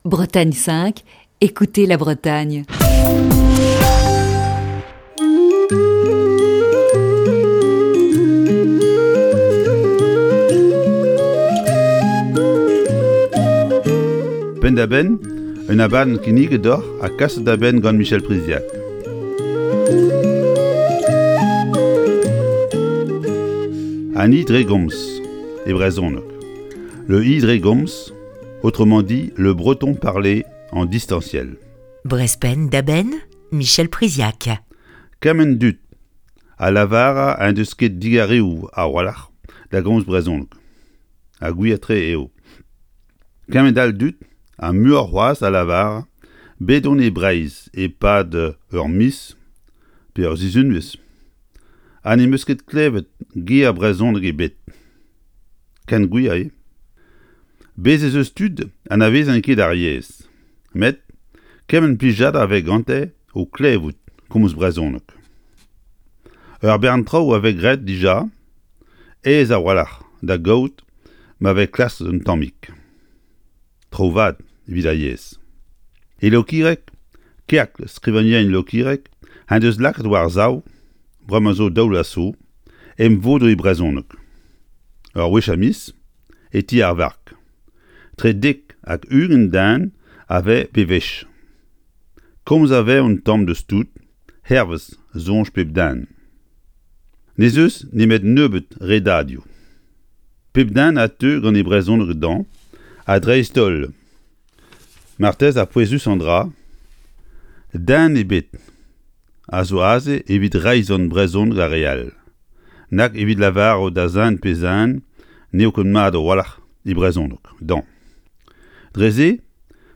Chronique du 16 avril 2021. Savez-vous ce qu'est le e-dregomz ?